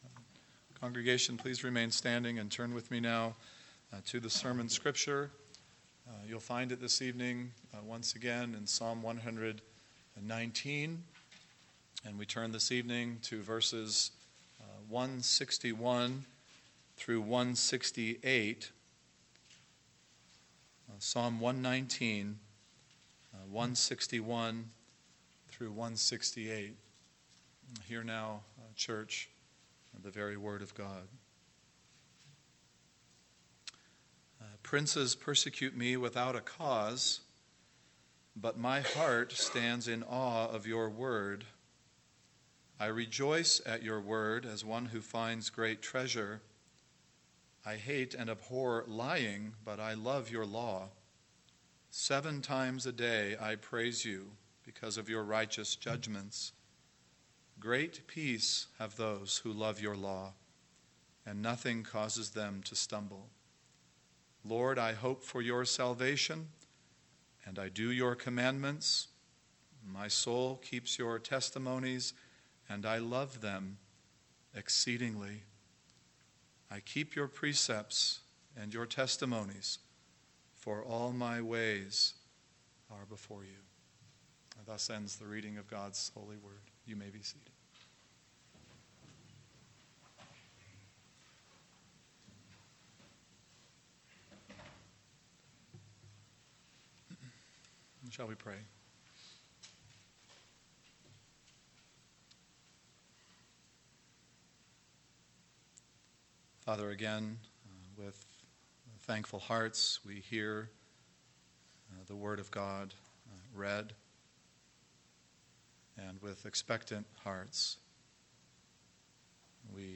PM Sermon – 9/1/2019 – Psalm 119:161-168 – Why Delight?